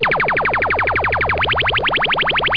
laser.mp3